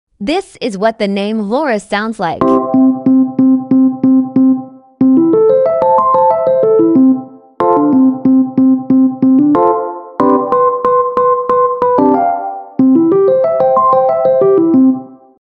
midi art